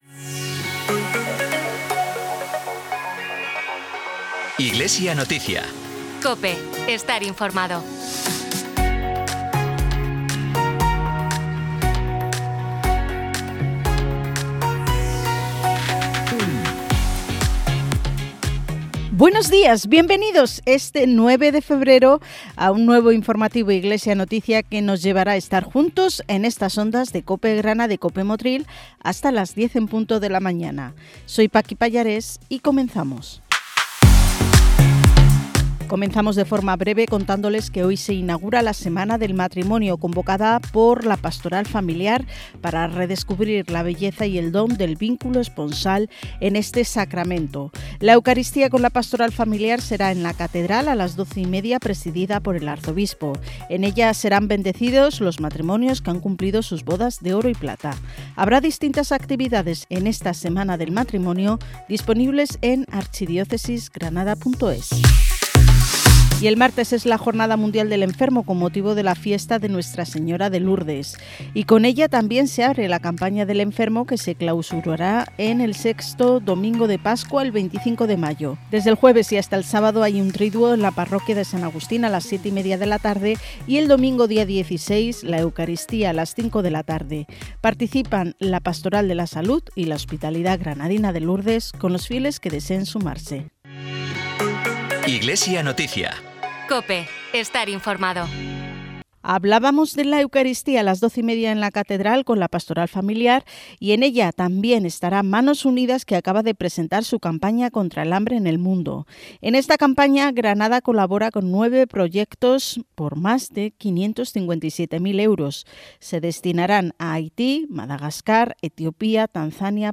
Disponible el informativo diocesano “Iglesia Noticia”, con la actualidad de la Iglesia en Granada, emitido en COPE Granada y COPE Motril el 9 de febrero de 2025.